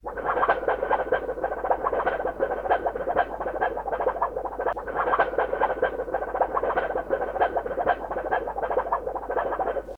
Tren_y_Redoble.wav